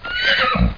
horse1.mp3